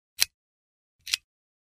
Звуки выключателя
Звук переключателя — включение и выключение